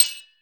pickaxe_damage.ogg